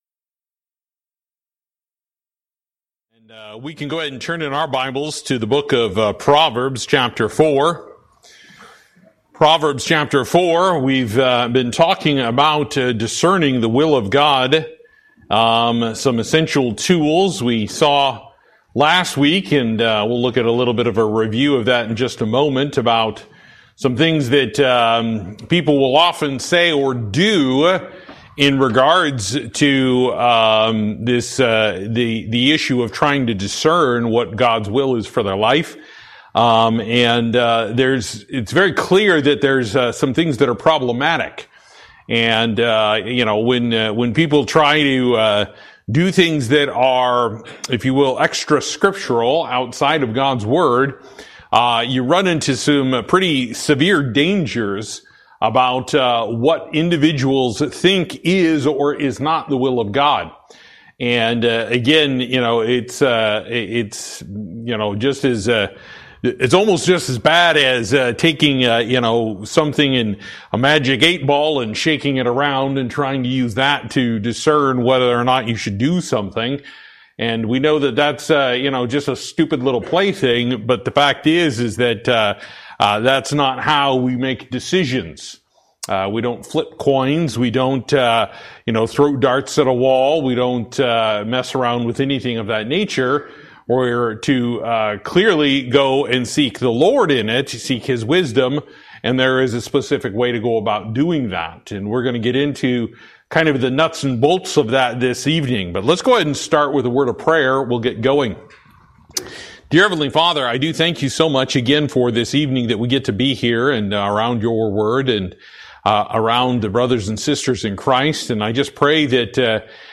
Service: Wednesday Night